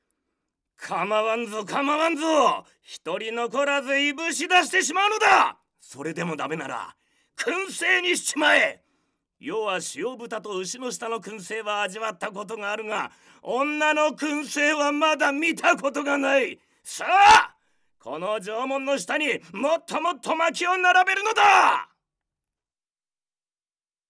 セリフA
ボイスサンプル